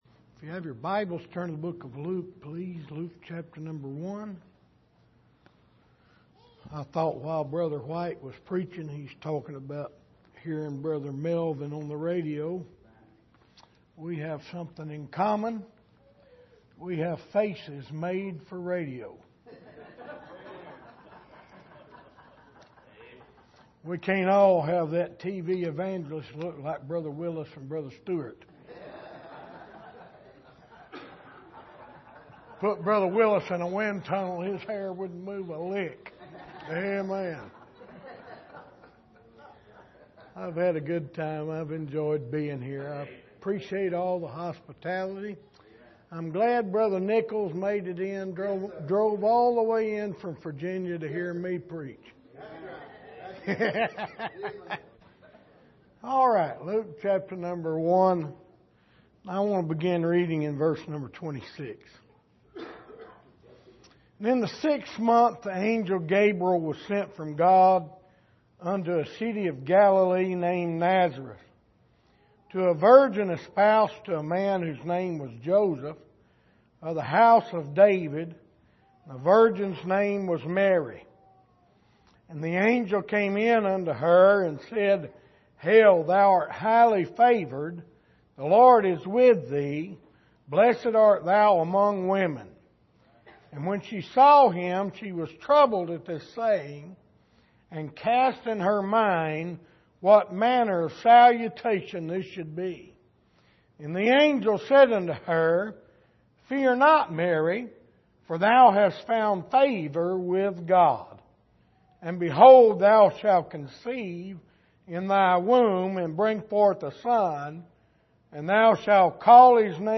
Luke 1:26-33 Service: Missions Conference Can God Trust You With Jesus?